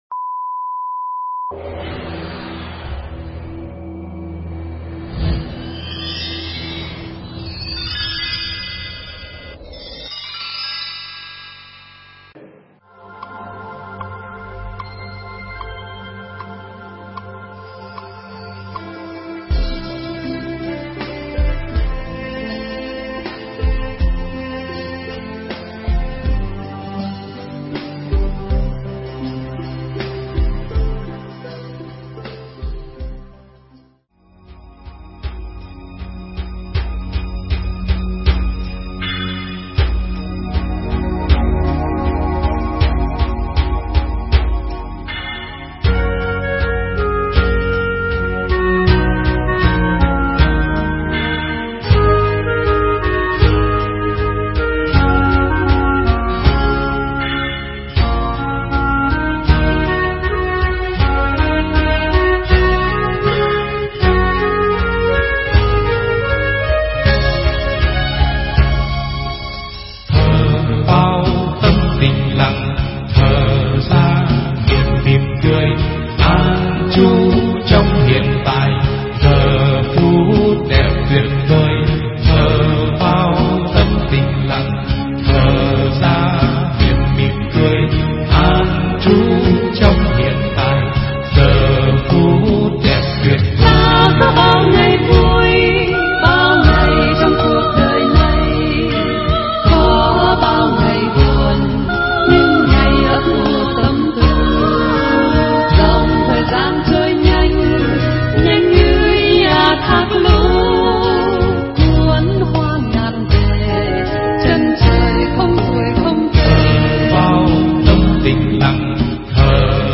Mp3 Pháp Thoại Niệm Thở Để Thành Phật